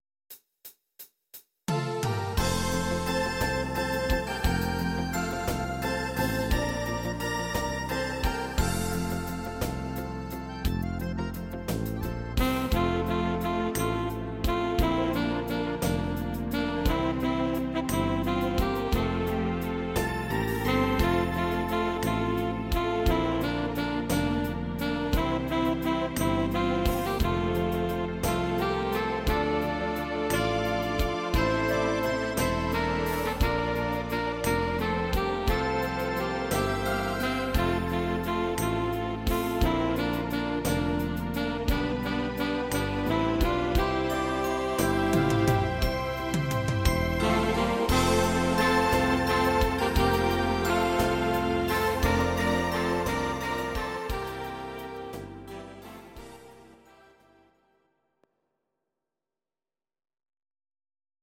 These are MP3 versions of our MIDI file catalogue.
Please note: no vocals and no karaoke included.
Your-Mix: Traditional/Folk (1154)